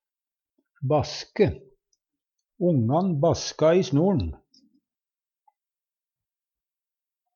Tilleggsopplysningar snorn - her må ein ty til lydfila, r-en i snor`n er ein mellomlyd -r og l baske fins og i nynorsk,